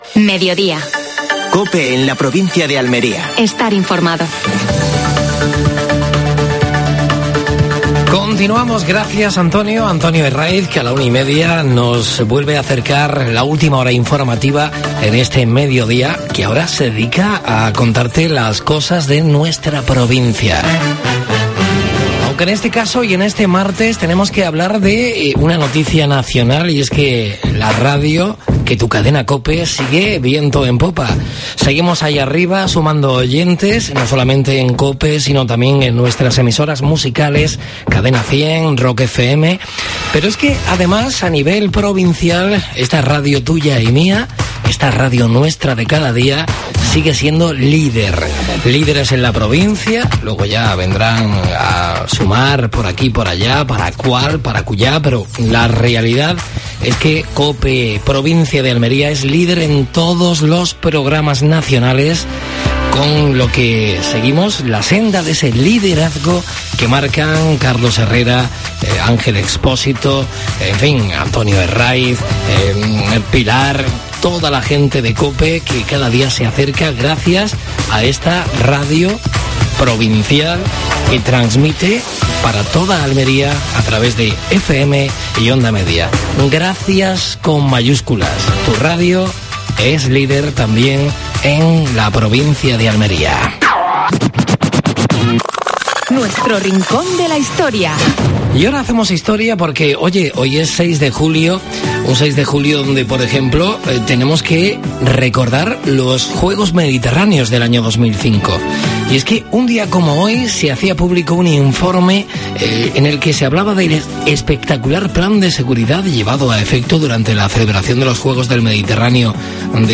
AUDIO: Actualidad de Almería. Entrevista a Manuel Martín Cuenca (director de cine). El tiempo, con José Antonio Maldonado. Última hora deportiva.